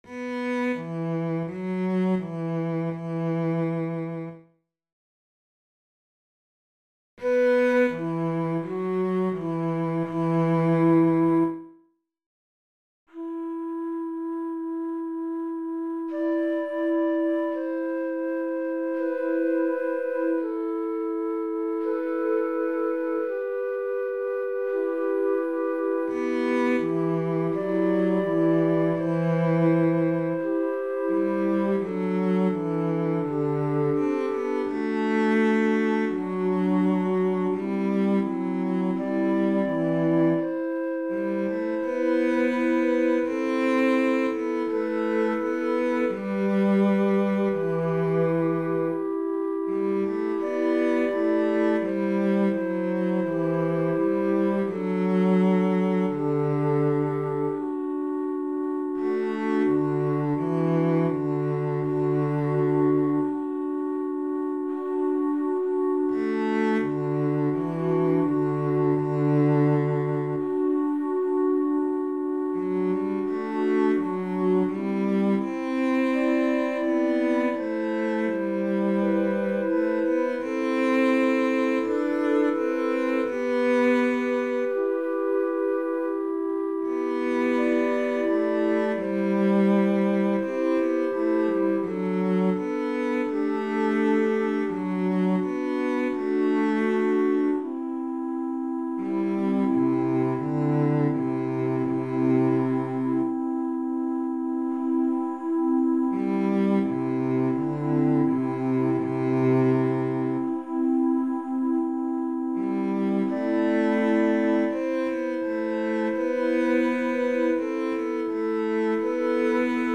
Voicing/Instrumentation: SSAATB
Choir with Congregation together in certain spots